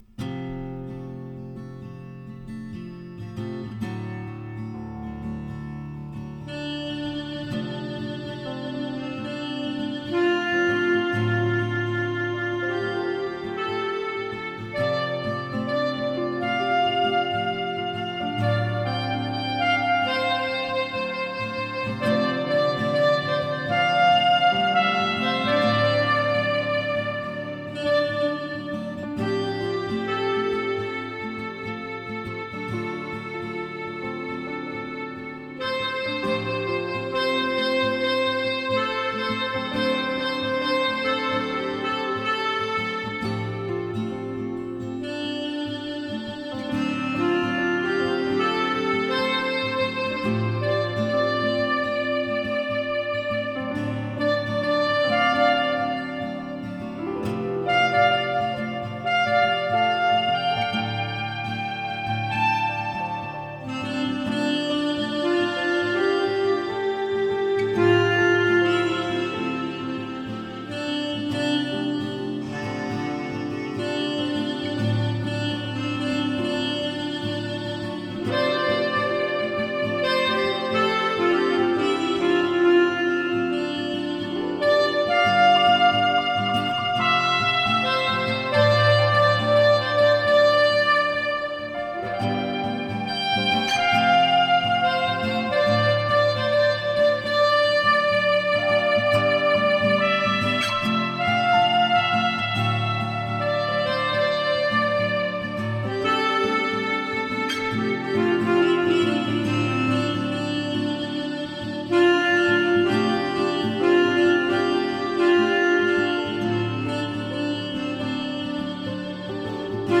Tempo: 60 bpm / Datum: 29.09.2016